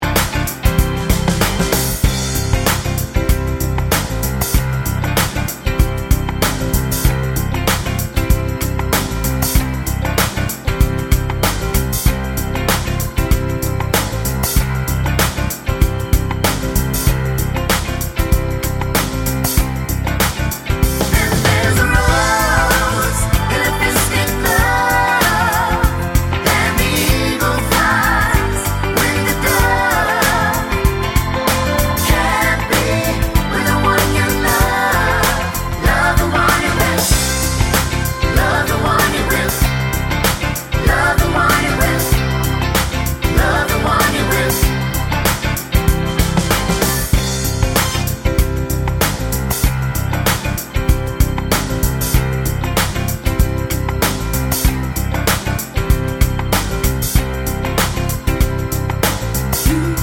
no Backing Vocals Soul / Motown 4:31 Buy £1.50